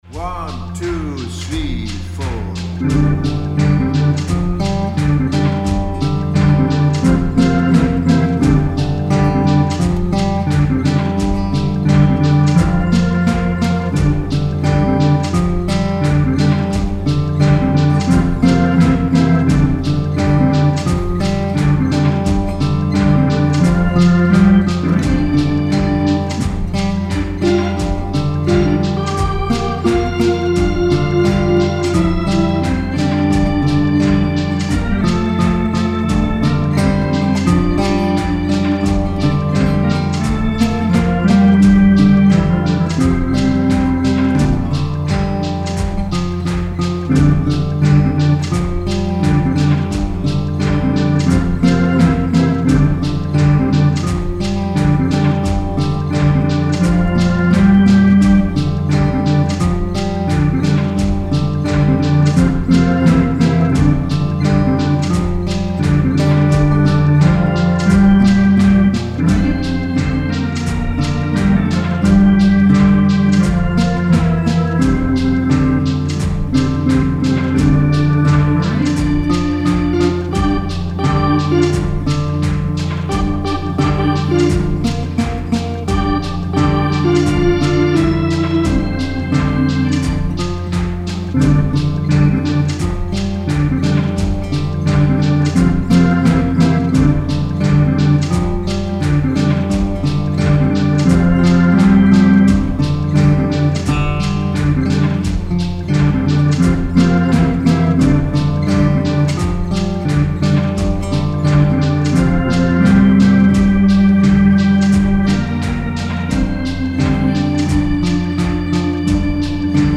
Tempo: 85 bpm / Datum: 11.03.2020